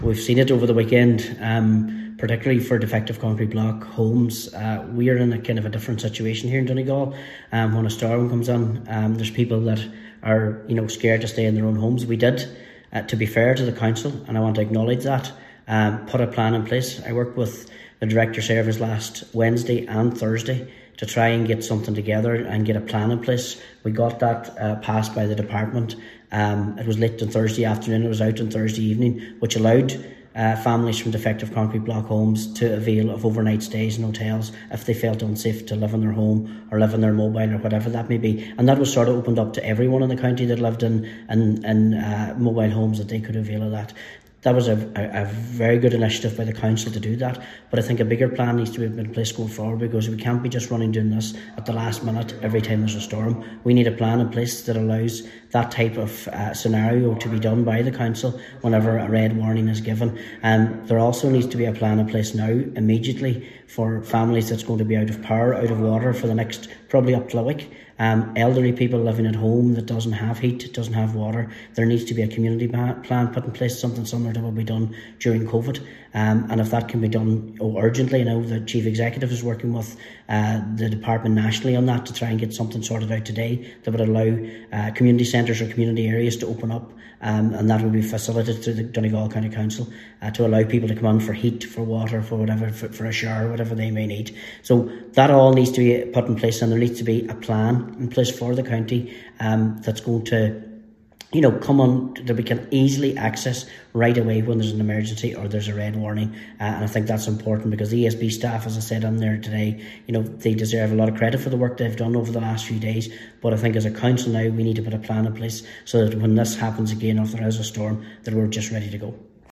Councillor Martin McDermott says preparation is key to ensure vulnerable people are not left wanting during adverse weather conditions, and has praised the council for its actions on Thursday evening.